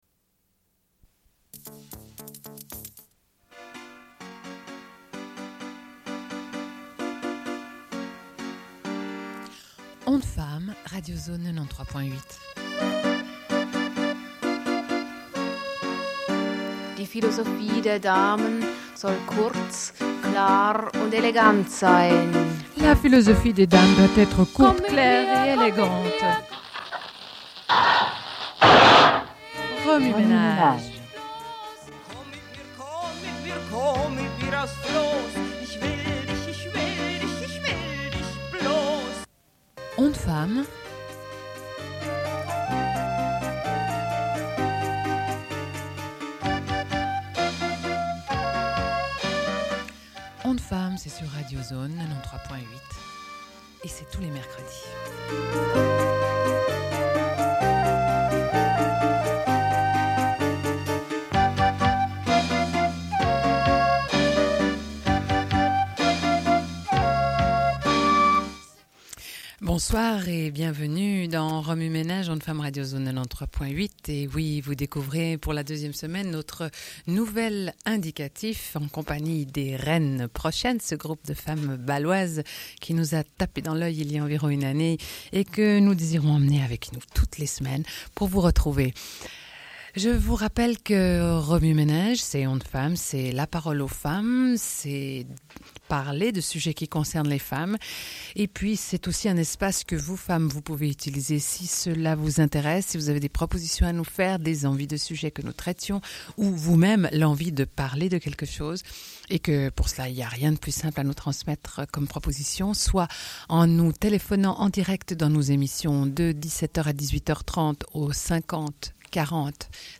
Une cassette audio, face A31:27